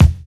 NY 12 BD.wav